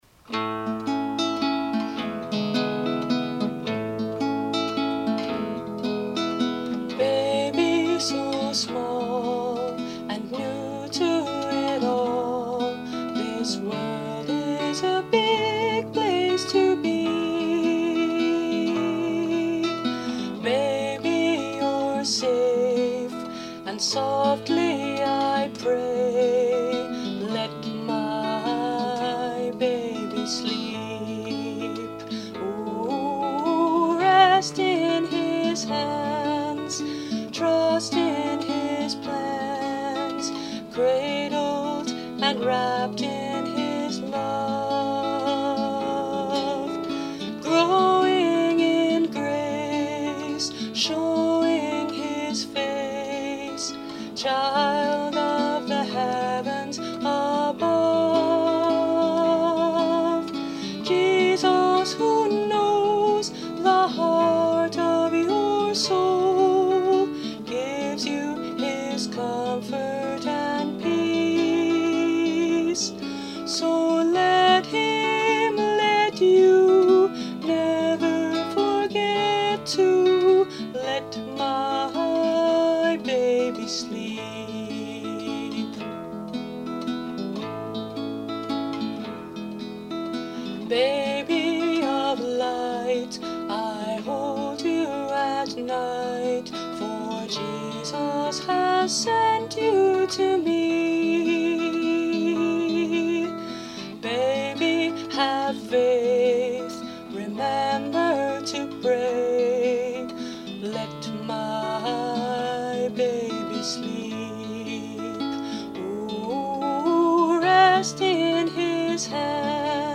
Remember that as you enjoy this lullaby.